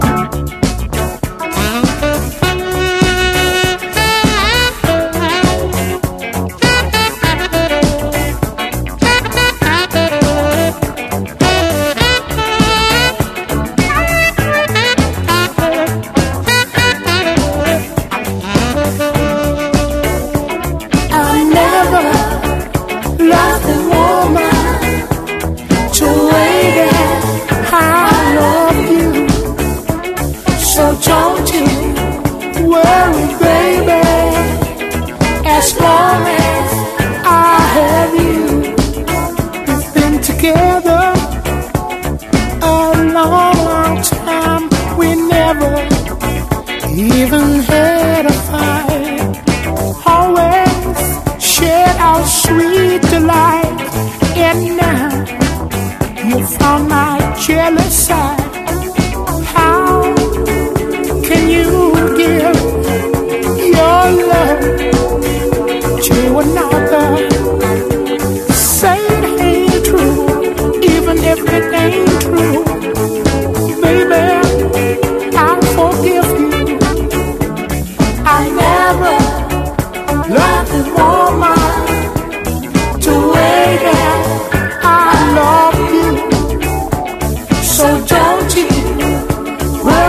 SOUL / SOUL / 70'S～ / DISCO
鮮やかなストリングス・アレンジ、ハリのある女性コーラスの勢いに踊りながら仰け反ってしまう、アッパーなディスコ・トラック！